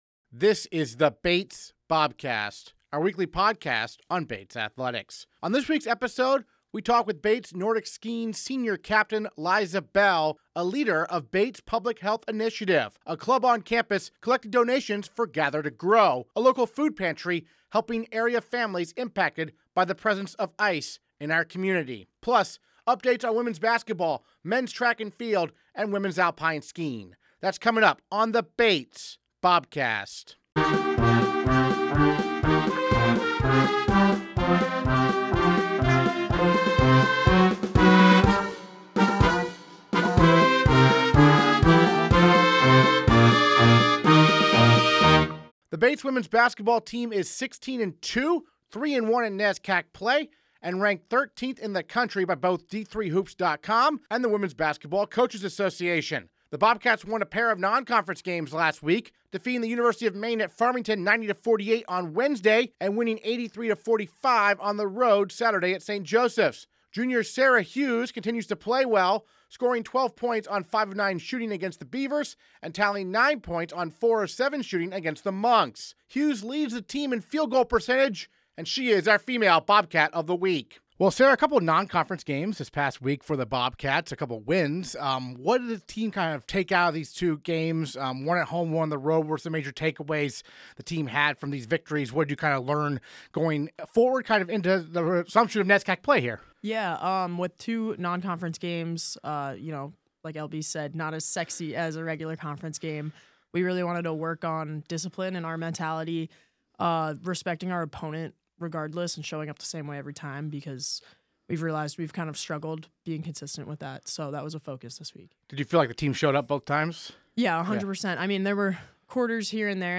Interviews this episode: